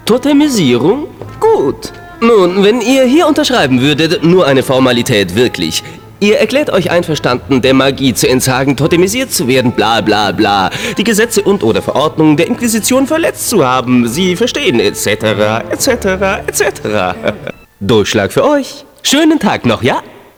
WARNUNG: Die deutsche Fassung ist unverhältnismäßig laut eingebunden worden, entsprechend sind auch die Samples teilweise recht laut.
Die Übersteuerungen sind echt ziemlich krass, da hätte man wirklich bei der Abmischung drauf achten können.